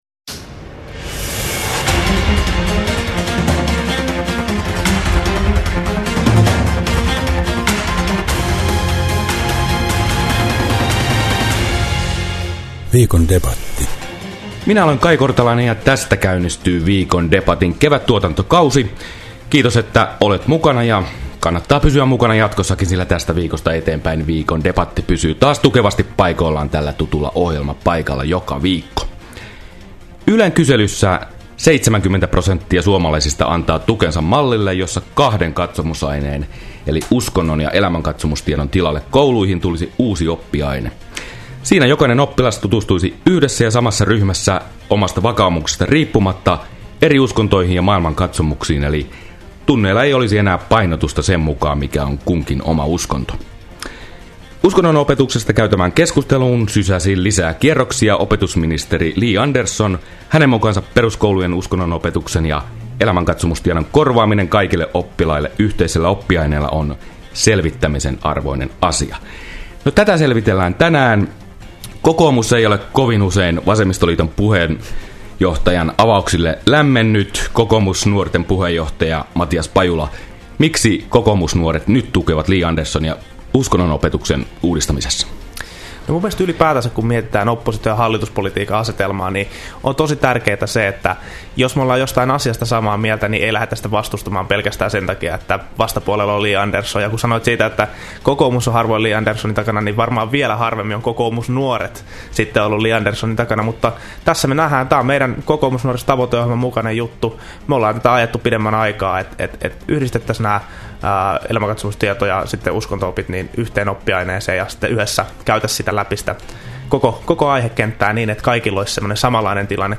KUUNTELE – Viikon debatti: Syrjäyttääkö uusi uskontotiedon oppiaine nykyisen uskonnonopetuksen?
Viikon debatti Radio Deissä keskiviikkona 22.1. kello 9.05. Uusinta samana päivänä kello 13.05 ja lauantaina kello 13.35. Kuuntele lähetys: